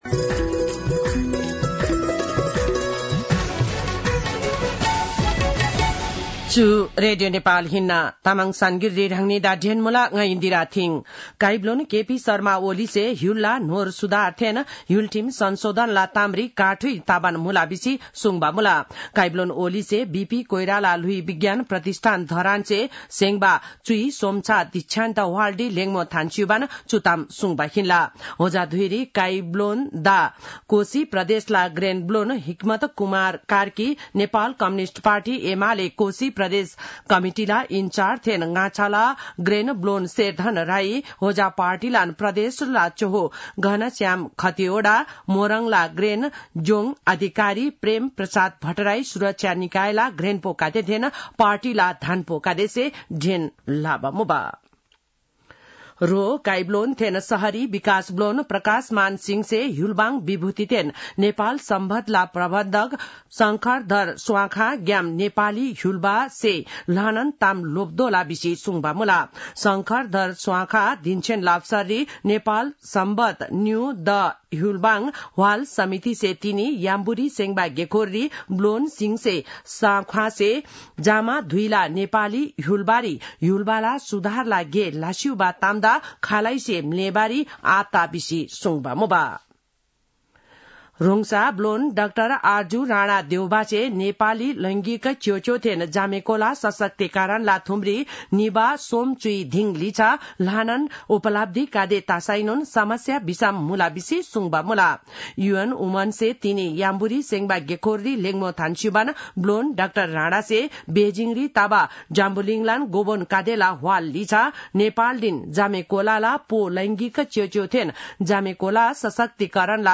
तामाङ भाषाको समाचार : २७ कार्तिक , २०८१